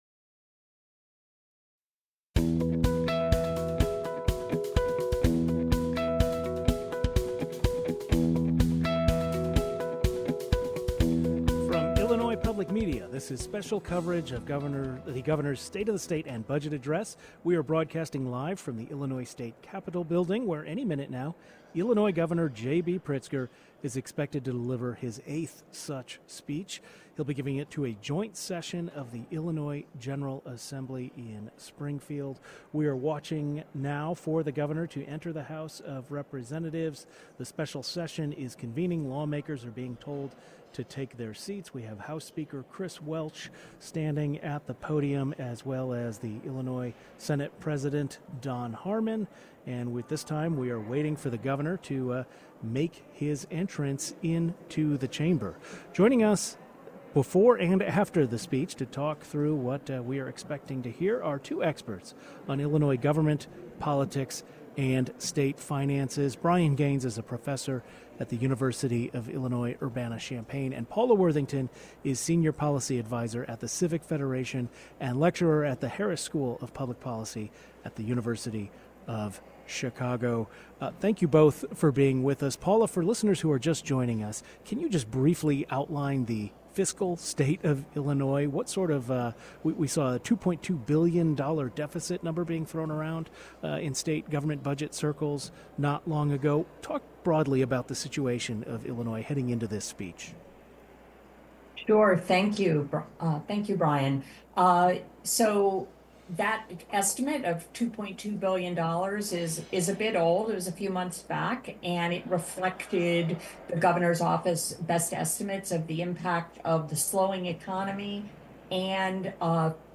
Governor JB Pritzker delivers his 2026 State of the State Address.
Two policy experts share their takeaways and analysis of the speech.
GovBudgetAddress2026.mp3